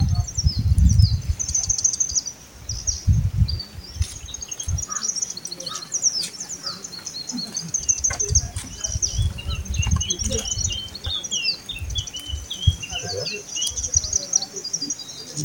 broad-tailed-grassbird-call